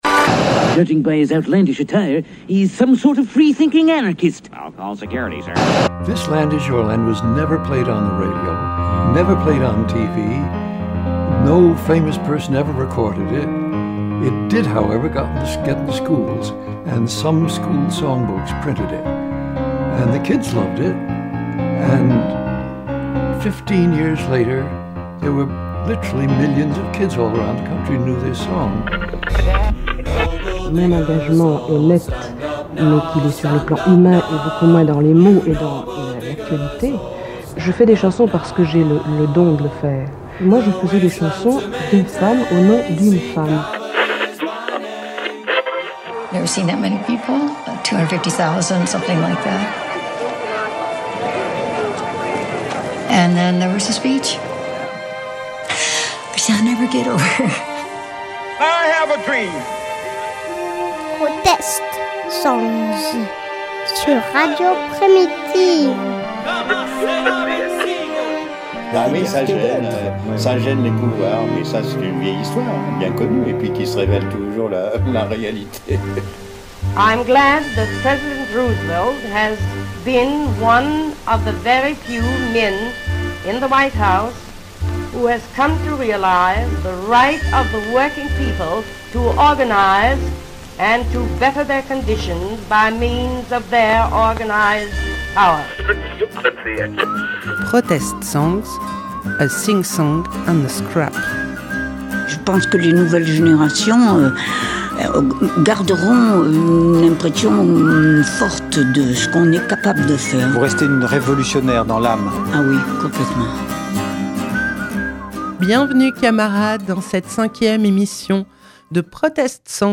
🎧 Émission 5 - Protest songs